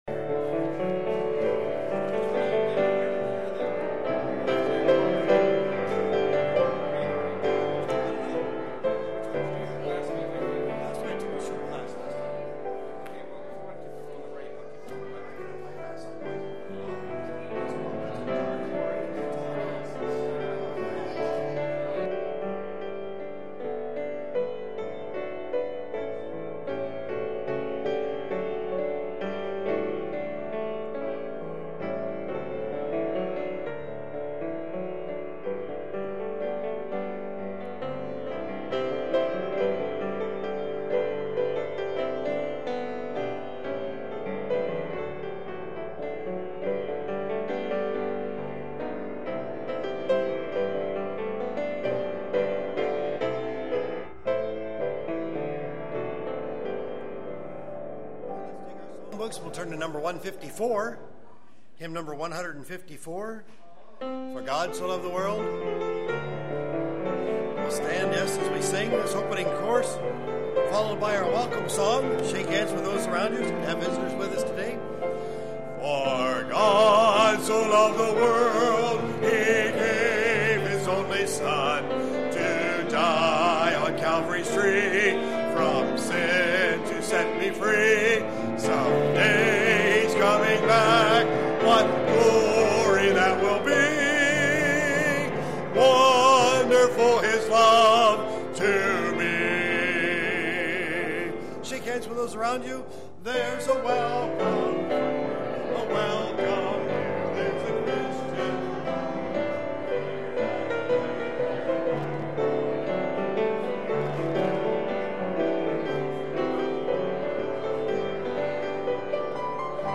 Kamloops, B.C. Canada
Morning Worship Service